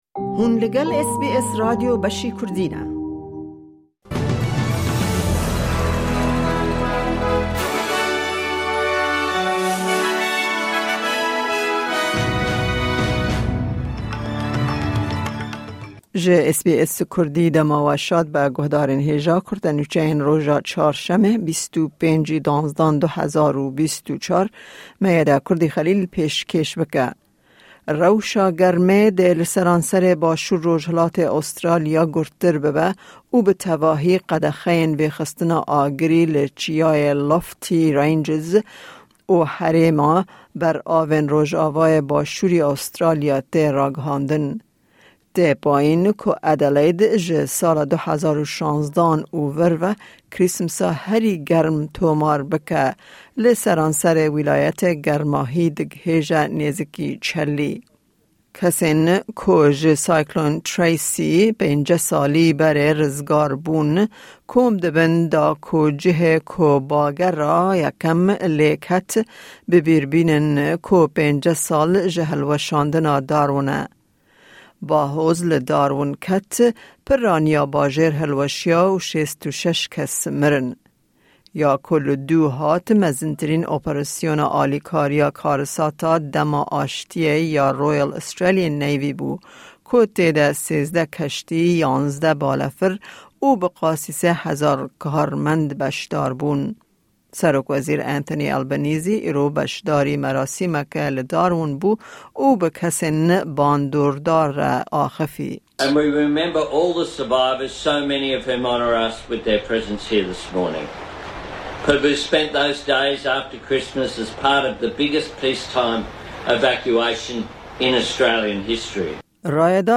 Kurte Nûçeyên roja Çarşemê 25î Kanûna 2024